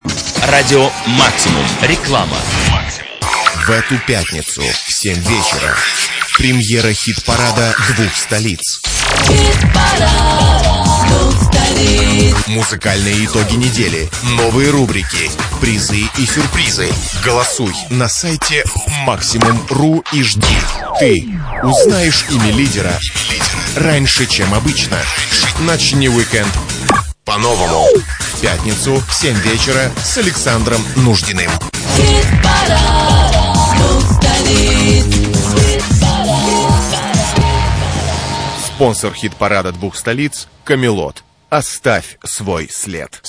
Рекламный блок (Радио Максимум, 24.02.2004) Хит парад двух столиц